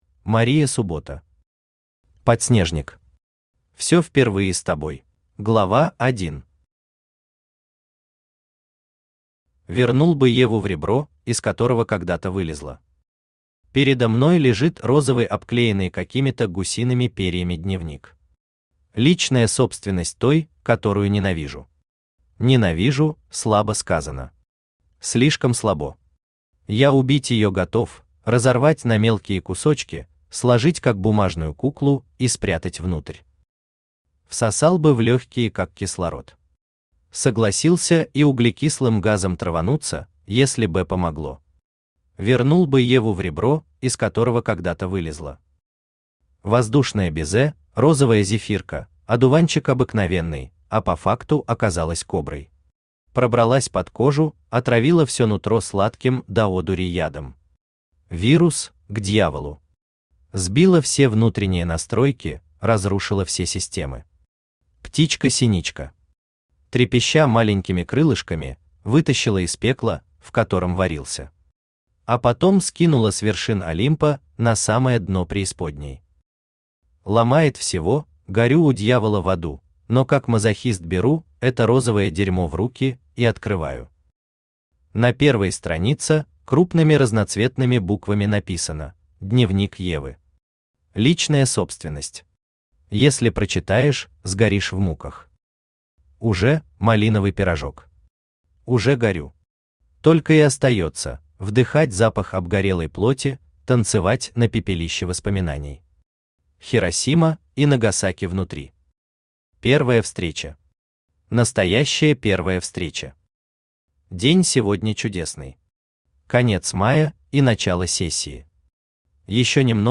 Все впервые с тобой Автор Мария Суббота Читает аудиокнигу Авточтец ЛитРес.